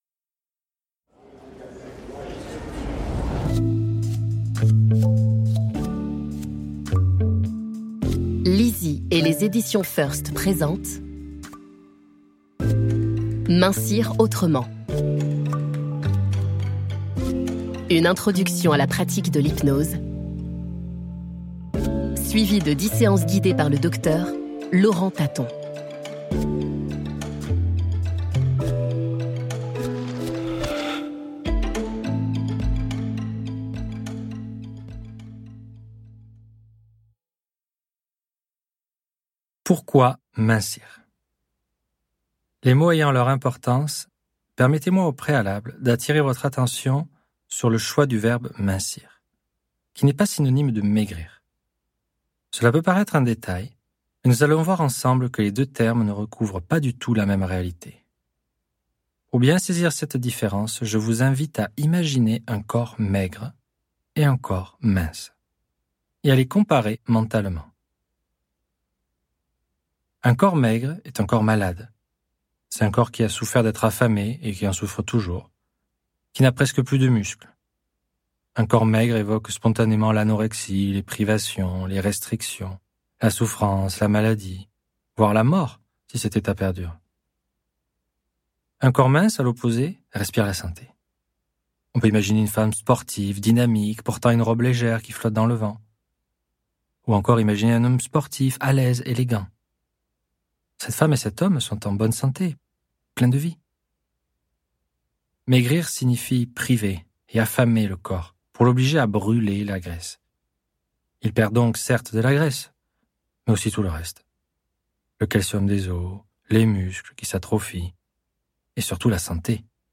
Installez-vous confortablement, laissez-vous guider par la voix d'un expert et prolongez votre écoute avec son livre compagnon !